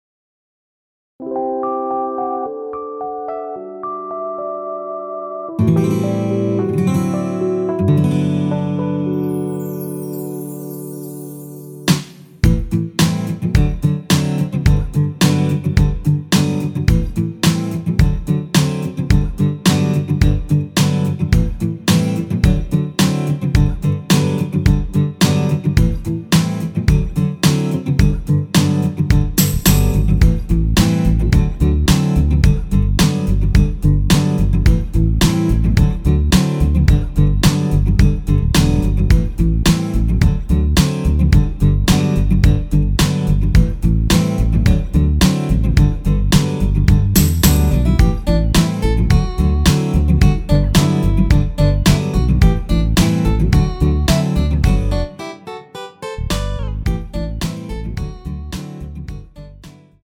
원키에서 (-2)내린 MR입니다.
앞부분30초, 뒷부분30초씩 편집해서 올려 드리고 있습니다.
중간에 음이 끈어지고 다시 나오는 이유는